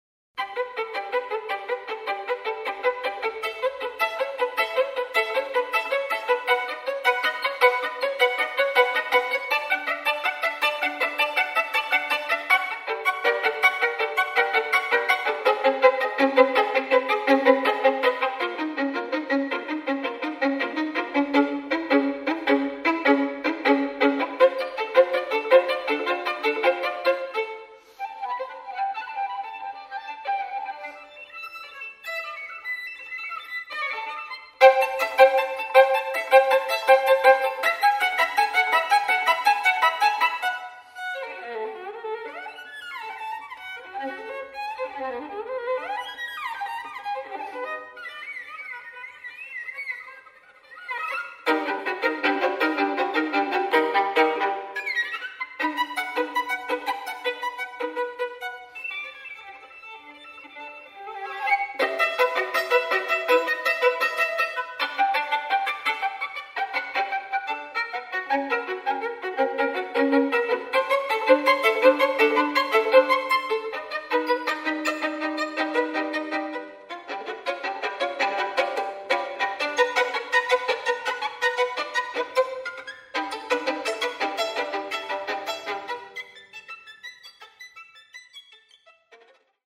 for solo violin